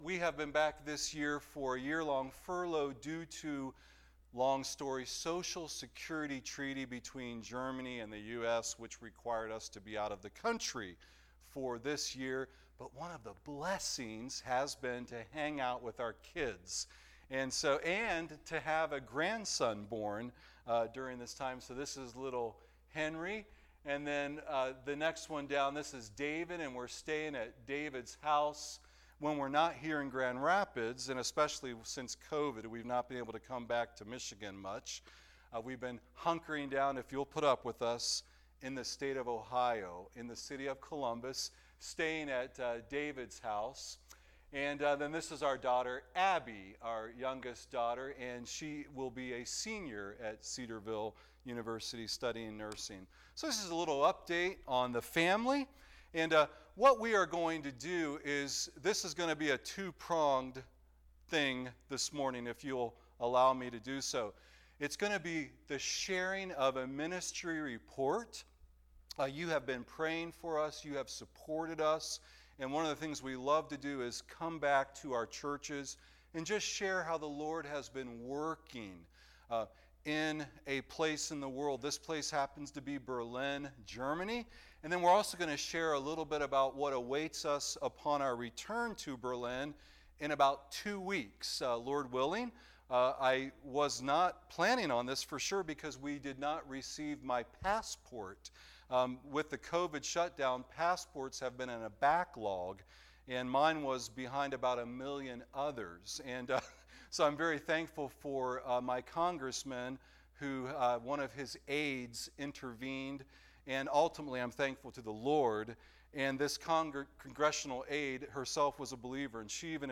A report and message from our missionaries to Germany.